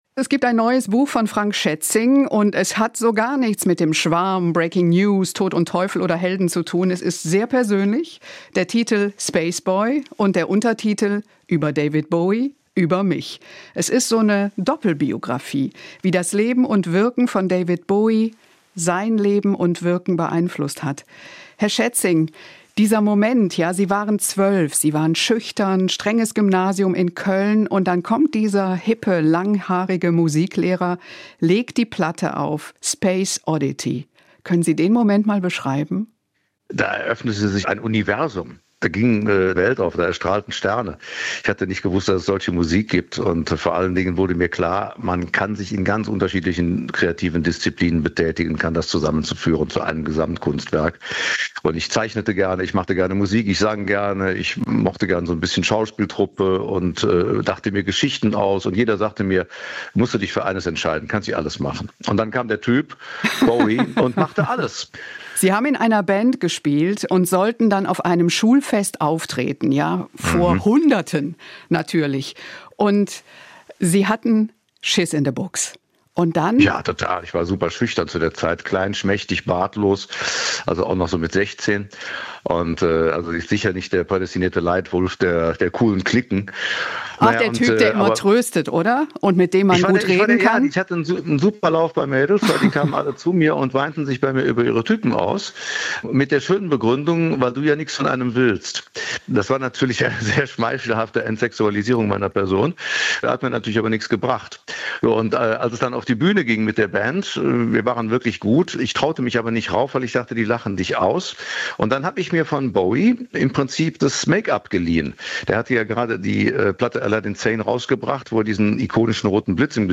Stattdessen ist "Spaceboy" sehr persönlich, eine Art Doppelbiografie darüber, wie das Leben und Wirken von David Bowie das Leben und Wirken von Schätzing beeinflusst hat. Warum Bowie für ihn so wichtig ist, hat er uns im Interview erzählt.
SWR1 Interviews